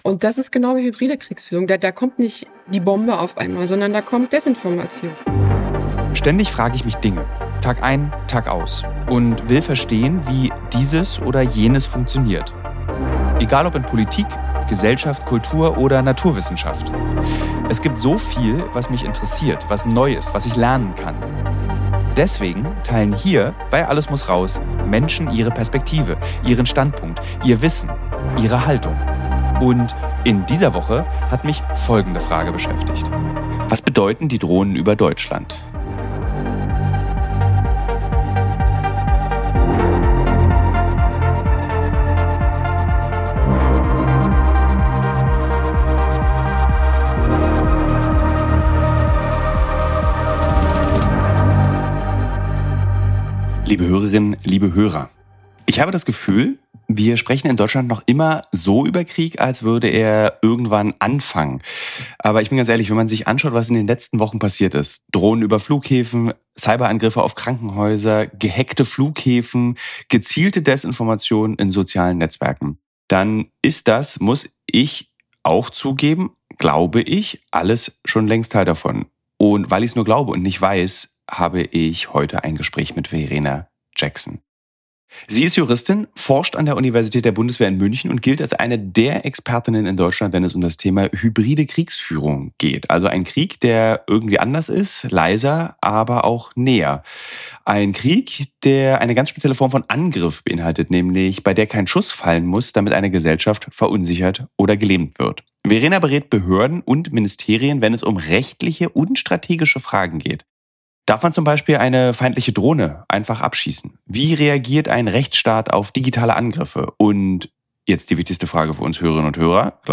Im Gespräch erklärt sie, warum Kriege heute nicht mehr nur mit Panzern geführt werden, sondern auch mit Desinformation, Drohnen und digitalen Angriffen. Es geht darum, was hybride Kriegsführung konkret bedeutet, wie verletzlich unsere Netze sind, warum rechtliche Hürden oft schnelleres Handeln verhindern – und wie sich jede:r von uns besser schützen kann.